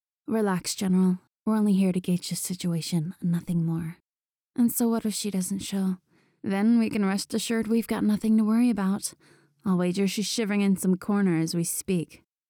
Voice: Mid-high, cunning, venomous undertone.